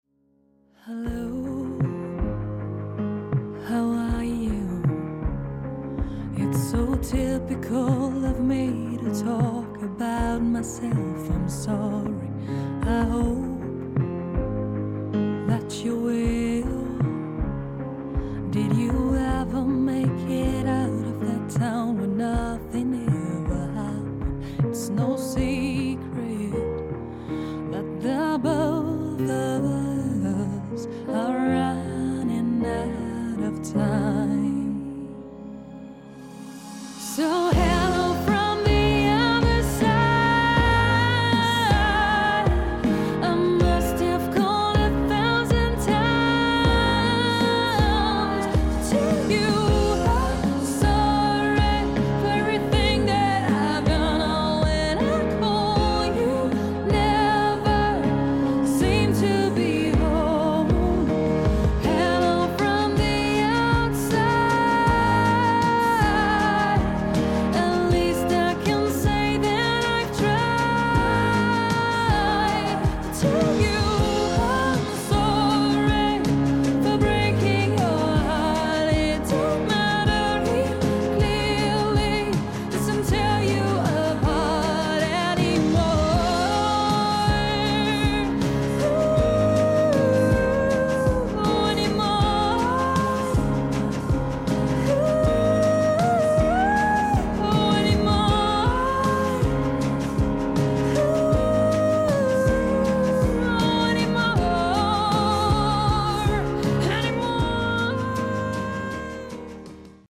COVERBAND
Dinnermusik